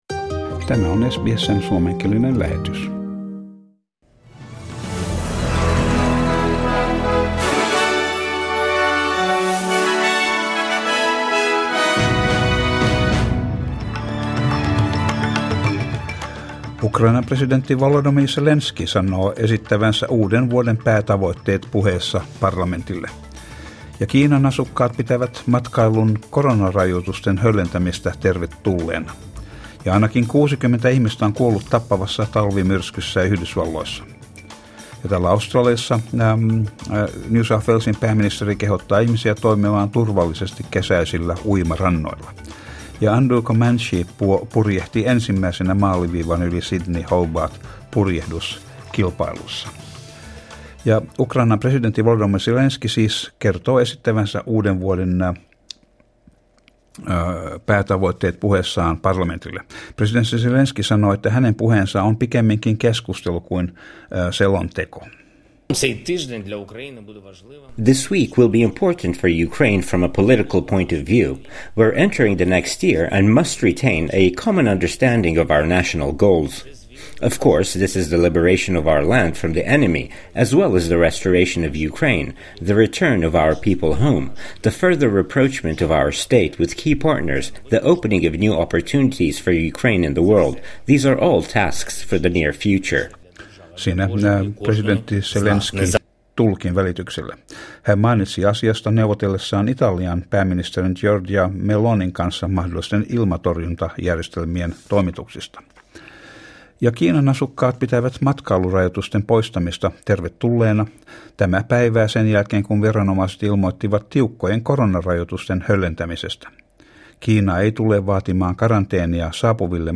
Uutiset ja sää 28.12.22